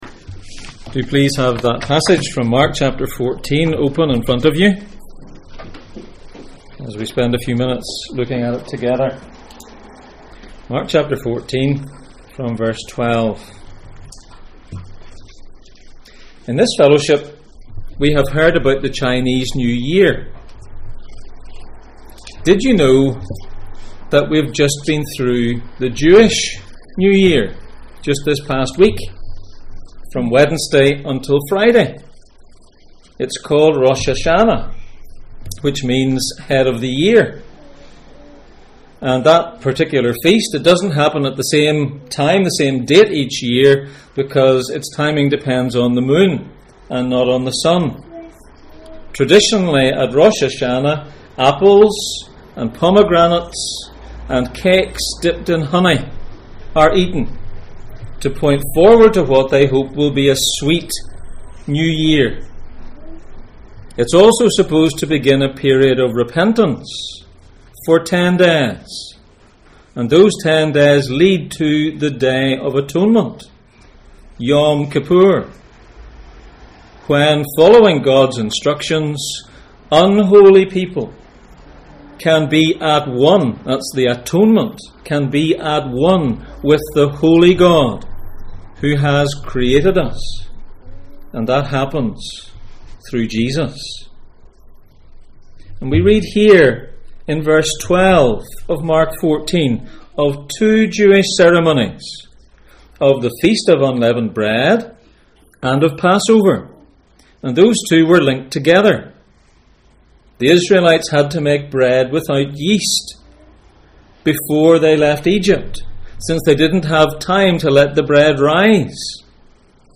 Jesus in Mark Passage: Mark 14:12-26 Service Type: Sunday Morning %todo_render% « Why did Judas betray Jesus?